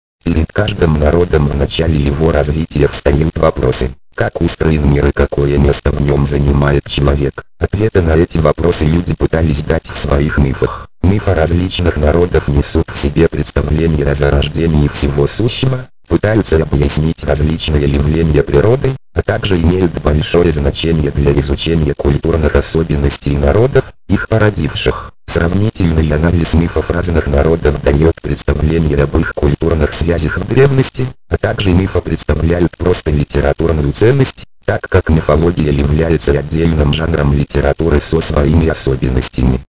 Речевой синтезатор Иван
Речевой синтезатор "Иван" - это адоптация под стандарт SAPI4 известного русскоязычного голоса "Диктор", разработанного Клубом голосовых технологий при научном парке МГУ в 1995 году для продукта "Speaking Mouse Home".
Он обладает выразительным и разборчивым голосом. Способен читать текст с интонацией, имеет собственный словарь произношения, включает в себя несколько профилей, которые можно настраивать и переключаться между ними и другие дополнительные сервисные возможности.
К сожалению, данная разработка так и не была окончена, из-за чего данный синтезатор обладает рядом погрешностей в работе, например, иногда случаются запинки в чтении на некоторых знаках и сочетаниях букв.